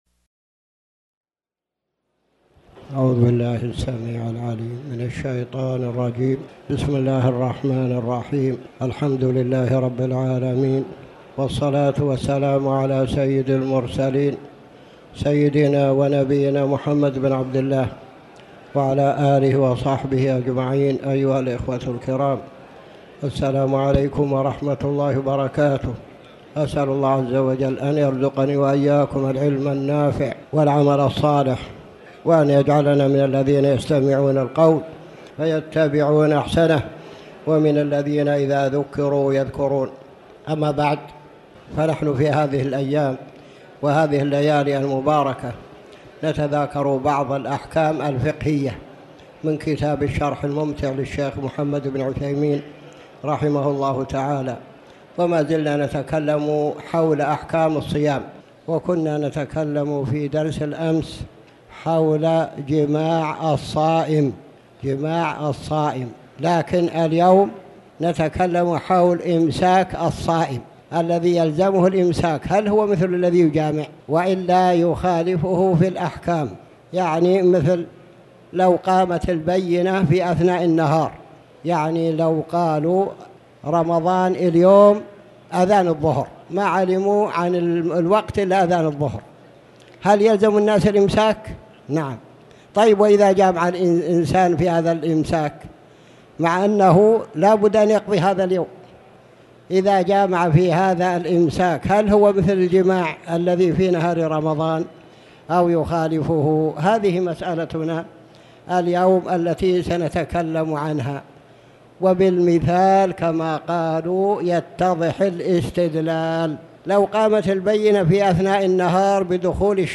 تاريخ النشر ٢٦ جمادى الأولى ١٤٣٩ هـ المكان: المسجد الحرام الشيخ